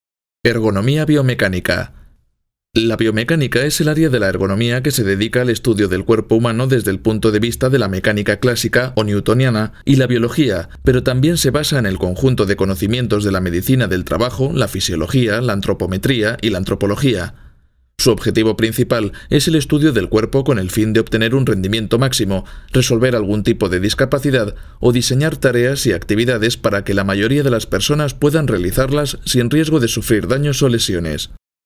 Si está buscando una voz joven, seria, amable y cálida para su proyecto de locución, yo puedo ofrecerle un servicio de calidad, rápido y económico.
Sprechprobe: eLearning (Muttersprache):
Castilian accent (native voice talent from Spain, living in Madrid), ideal if you are looking for an european spanish accent or if your target market is Spain. Warm, deep and sensual for commercial and promos; warm, deep and serious for institutional promos, presentations, etc. Younger voice for other kind of projects.